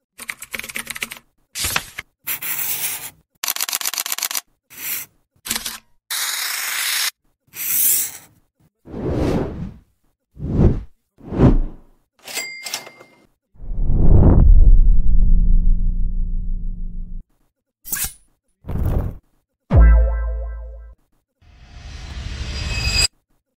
Âm thanh Hồi sinh, Sức sống, Đâm chồi, Cây mọc, Năng lượng… Tiếng Gà vỗ cánh phành phạch và Gáy…
Thể loại: Hiệu ứng âm thanh
Description: Âm thanh, Sound Effect thường xuất hiện trong các video của kênh Người Quan Sát là những hiệu ứng âm thanh đặc trưng, tạo điểm nhấn cho tình huống, tăng kịch tính hoặc gây hài hước.